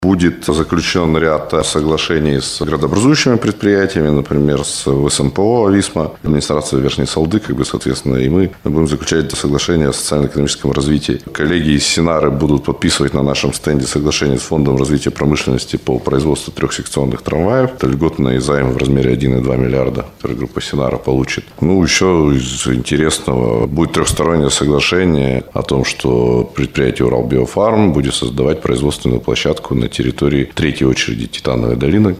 Об этом рассказал министр инвестиций и развития Свердловской области Дмитрий Ионин на пресс-конференции «ТАСС-Урал» в преддверии выставки «Иннопром».
707-Ионин.mp3